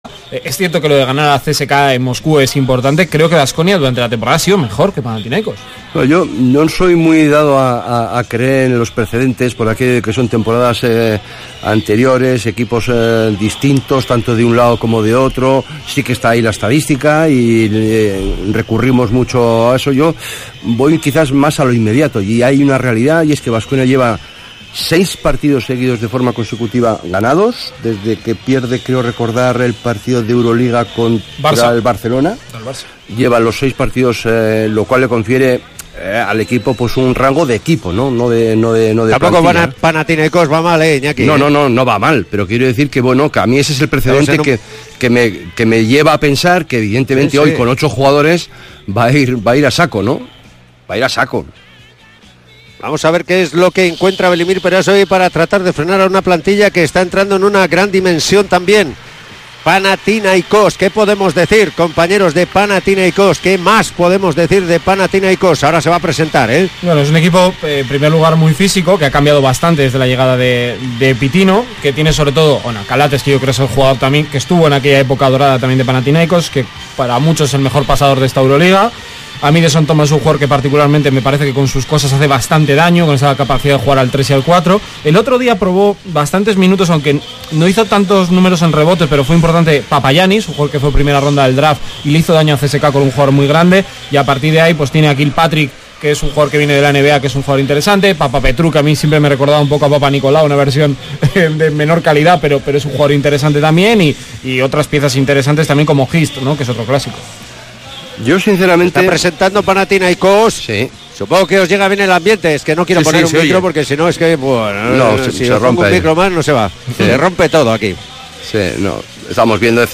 Panathinaikos-Baskonia jornada 27 euroleague 2018-19 retransmisión Radio Vitoria